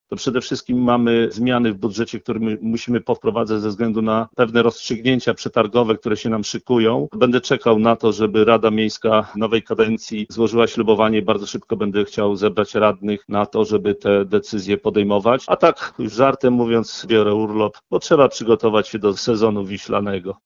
Komentując wynik wyborów burmistrz podziękował wszystkim tym, którzy wzięli udział w niedzielnym głosowaniu, oraz którzy poparli jego kandydaturę. Jak dodaje, przed samorządem nowej kadencji sporo pracy.